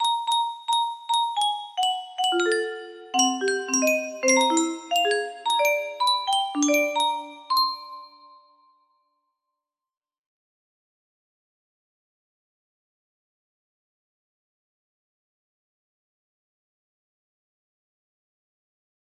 hiiiiiiiiiiiiiiiiiiiiiiiiiiiiiiiaaaaaaaaaaaa music box melody